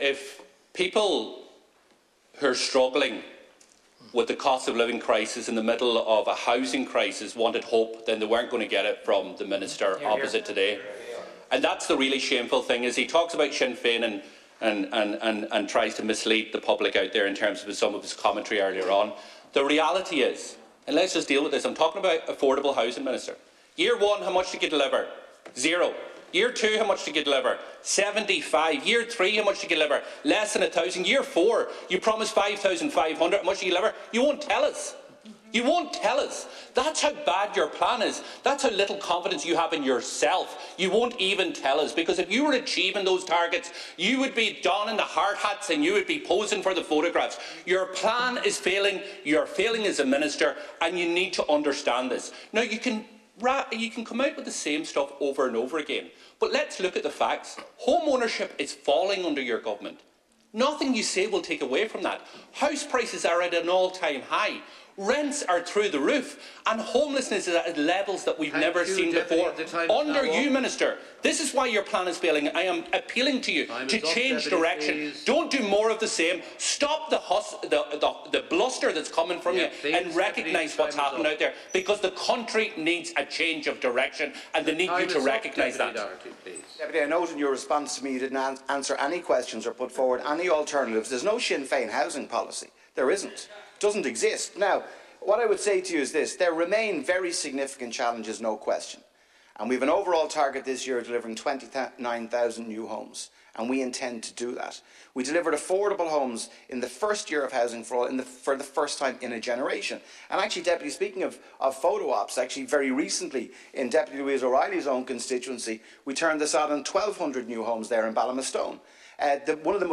Deputy Doherty and Minister O’Brien clash in regular Thursday Dail session
The Ceann Comhairle intervened during Leader’s Questions telling Deputy Doherty that he was out of order.
pearse-dail.mp3